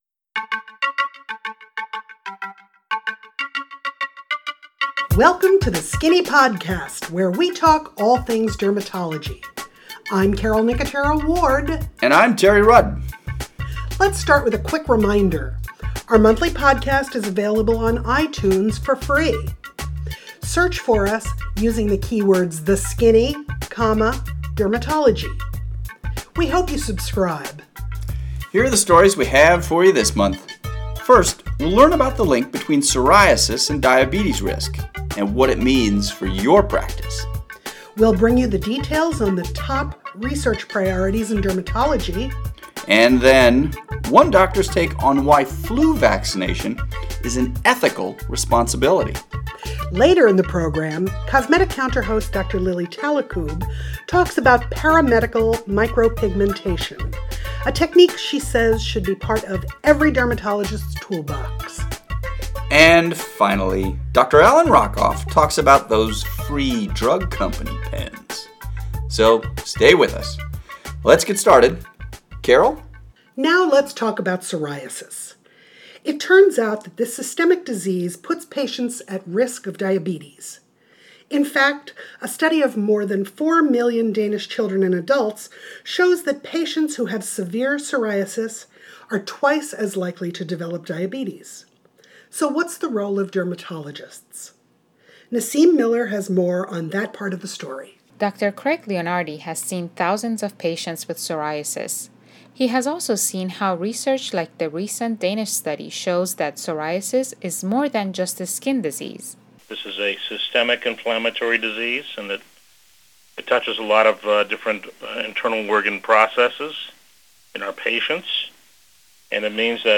We also bring you an interview with a physician that believes flu vaccination is an ethical responsibility.